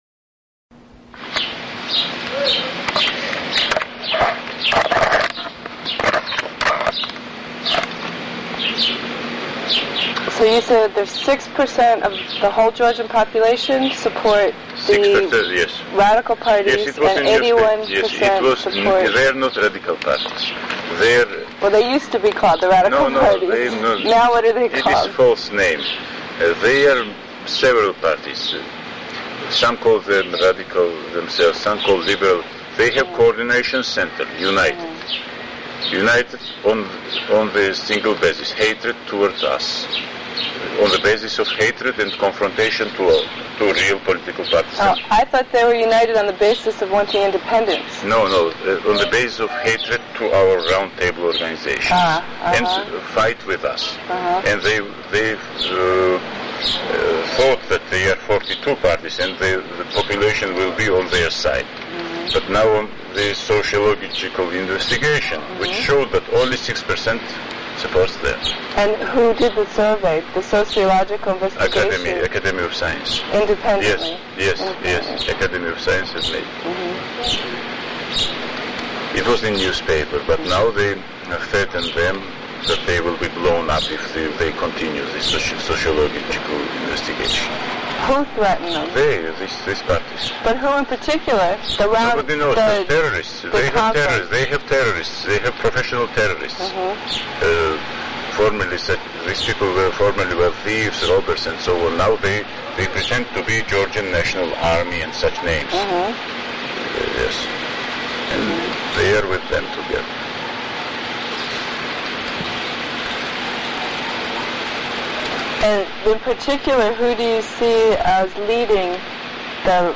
ზვიად გამსახურდიას დღემდე უცნობი ინტერვიუ